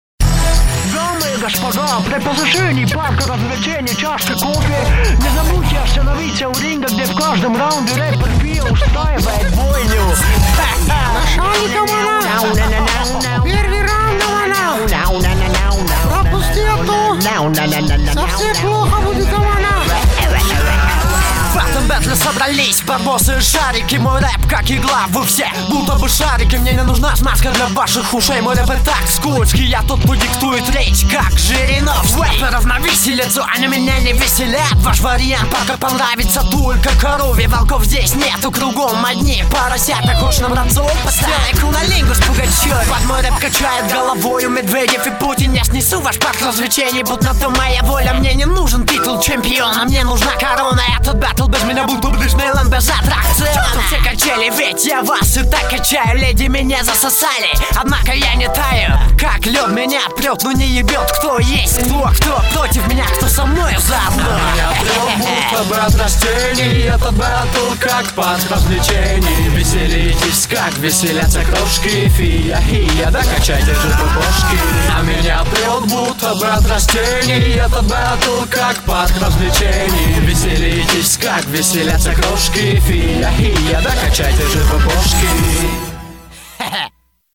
Главная » Каталог mp3 » Рэп / HIP HOP » Tajik Rap